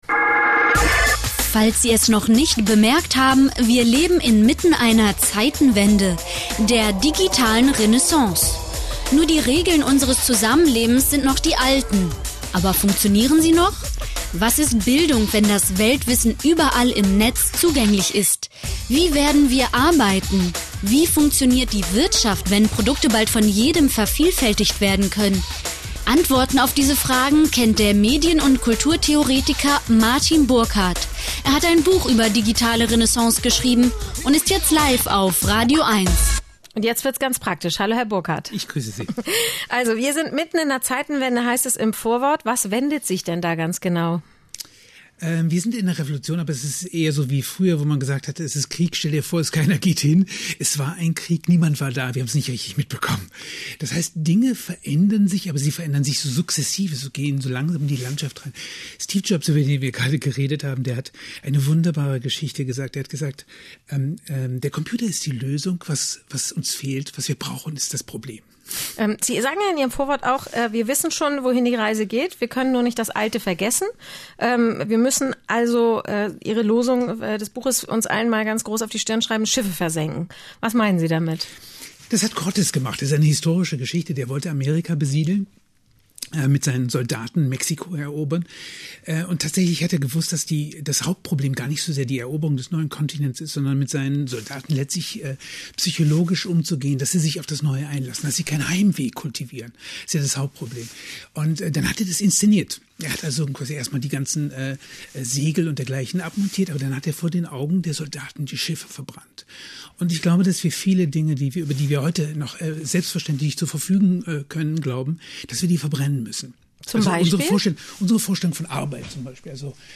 im Gespräch mit radio eins